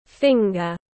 Finger /ˈfɪŋ.ɡər/